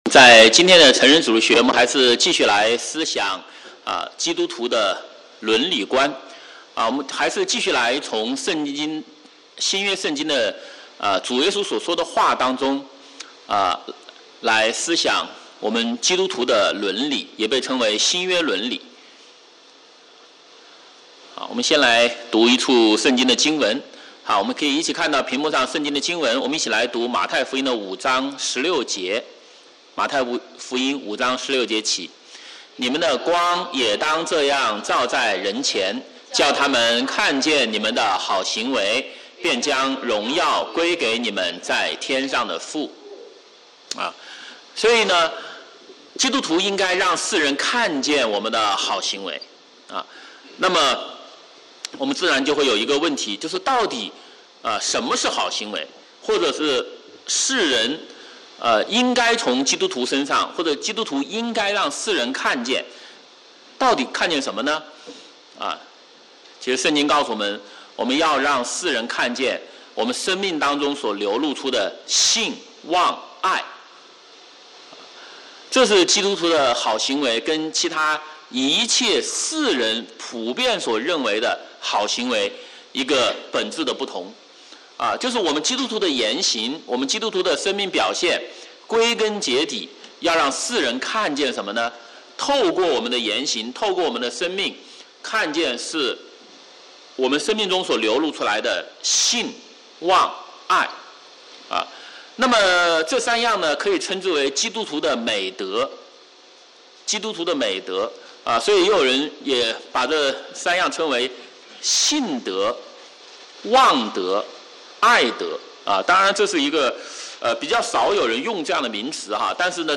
Sunday Sermons (Chinese)